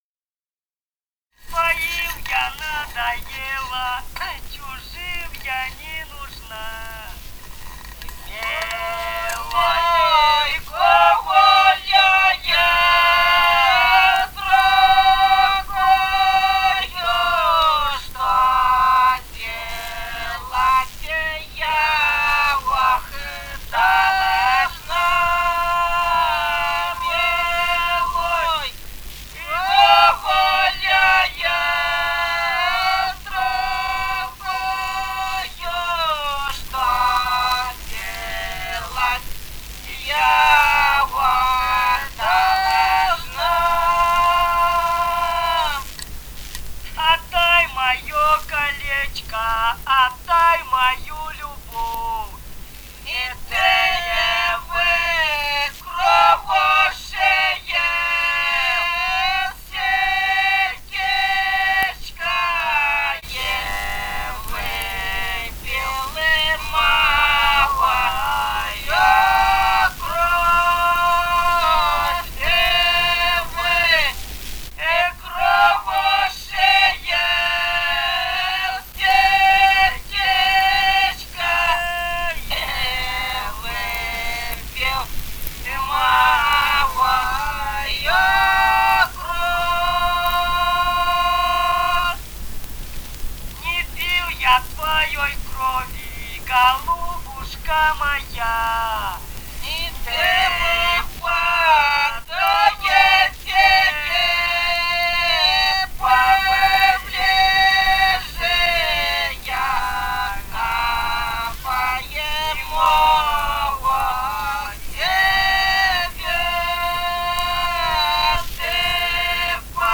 полевые материалы
«Своим я надоела» (лирическая).
Бурятия, с. Желтура Джидинского района, 1966 г. И0904-10